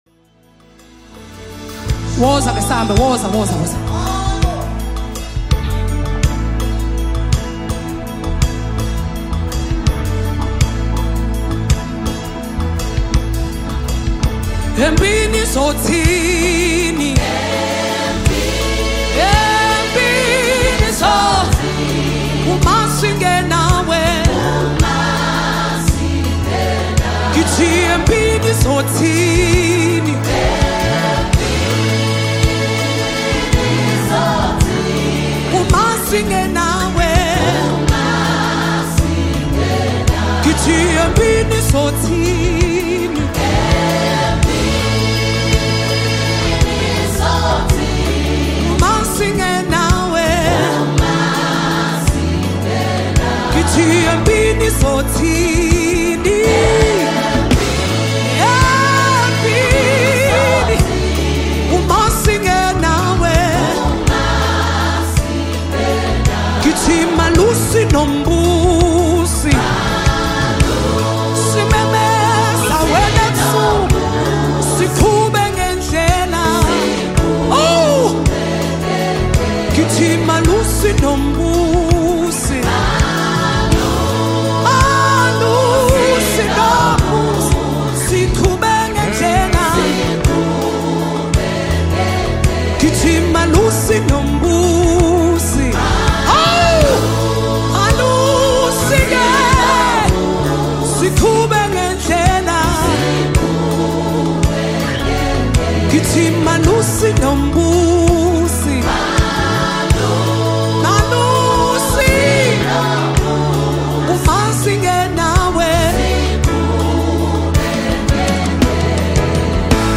• Genre: Gospel